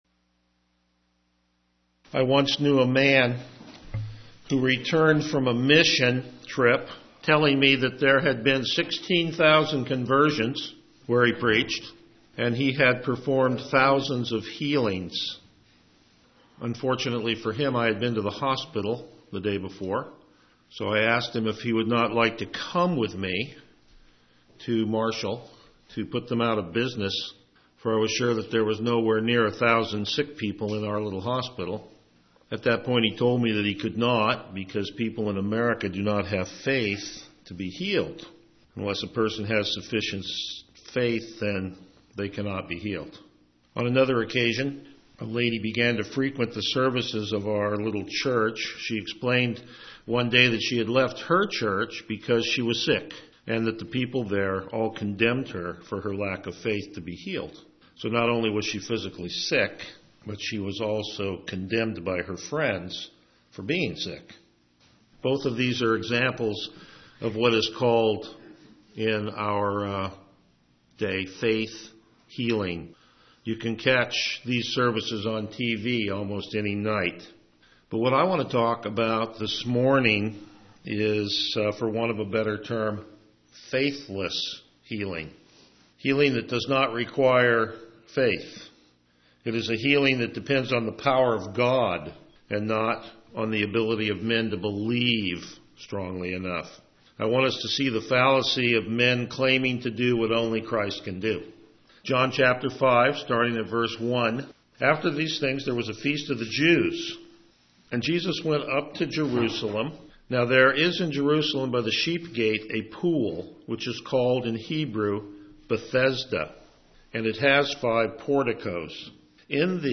January 20, 2013 Faithless Healing Series: The Gospel of John Passage: John 5:1-15 Service Type: Morning Worship Download Files Notes Topics: Verse By Verse Exposition « Signs and Wonders…to what end?